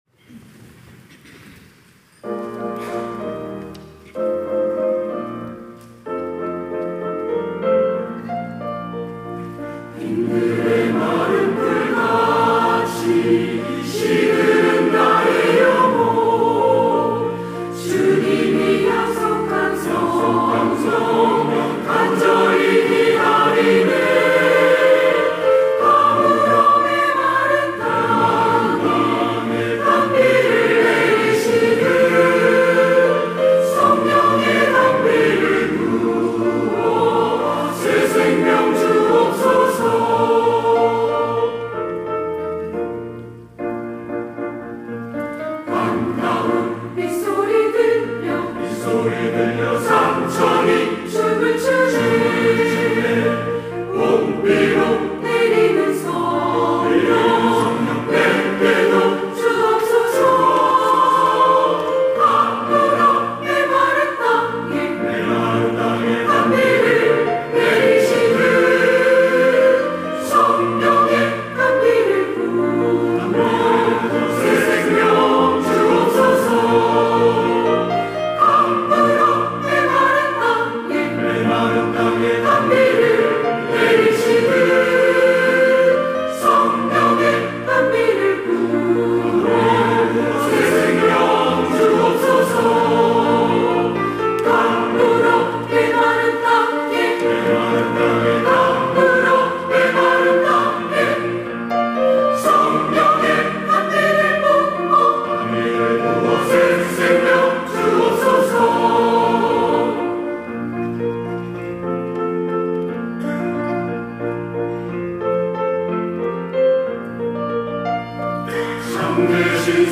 시온(주일1부) - 빈 들에 마른 풀 같이
찬양대